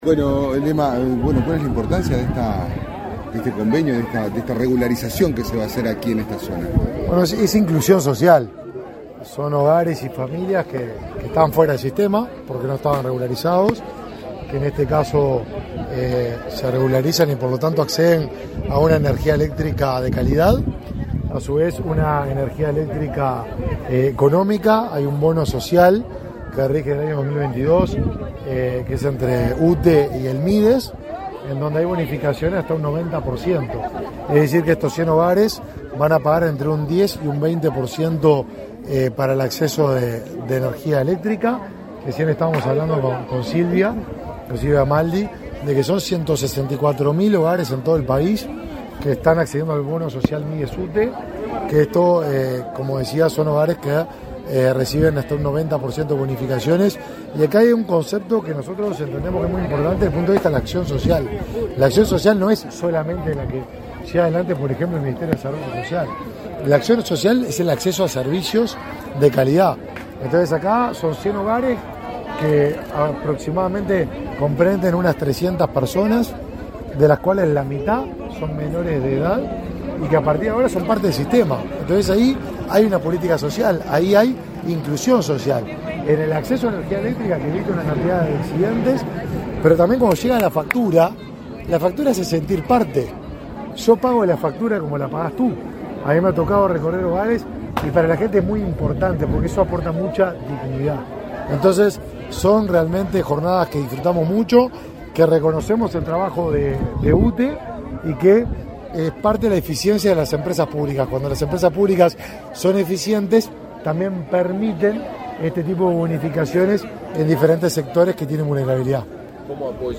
Declaraciones a la prensa del ministro de Desarrollo Social, Martín Lema
Declaraciones a la prensa del ministro de Desarrollo Social, Martín Lema 12/12/2023 Compartir Facebook X Copiar enlace WhatsApp LinkedIn Tras participar en la inauguración de obras de electrificación rural en Montevideo, este 11 de diciembre, en el marco del Programa de Inclusión Social, el ministro Martín Lema realizó declaraciones a la prensa.